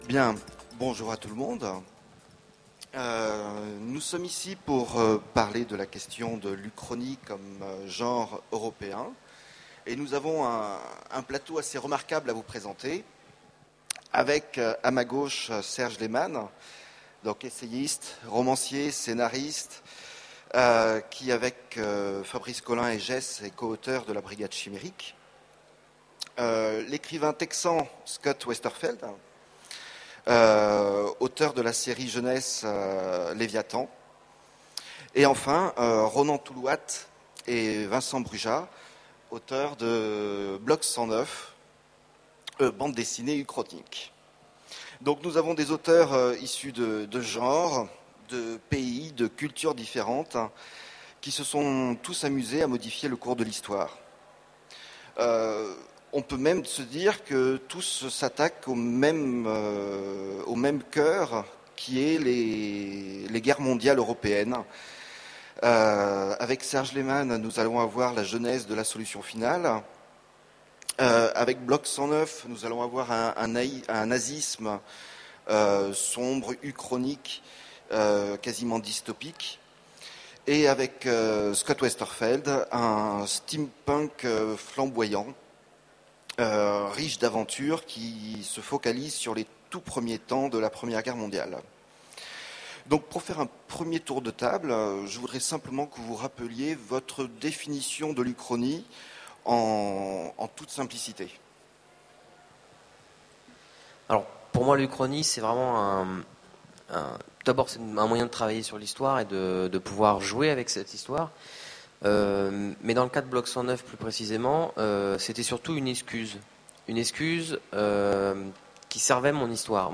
Voici l'enregistrement de la conférence L'Uchronie, un genre européen ? aux Utopiales 2010.